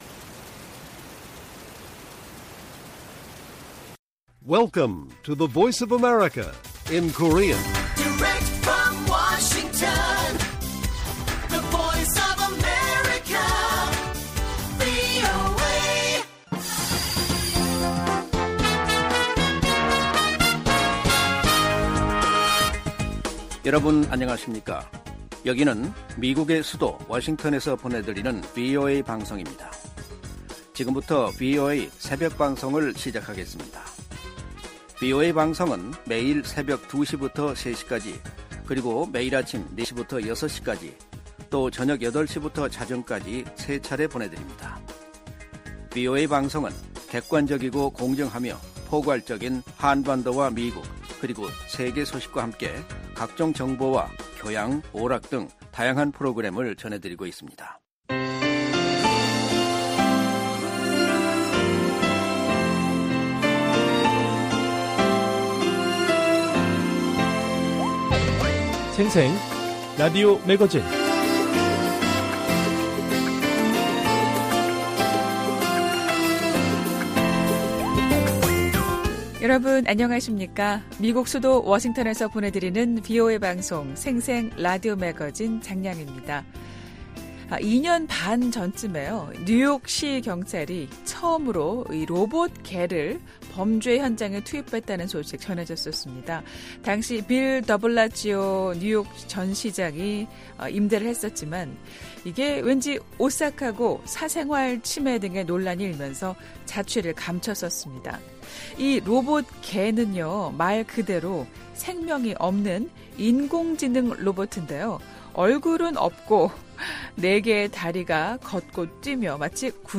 VOA 한국어 방송의 월요일 새벽 방송입니다.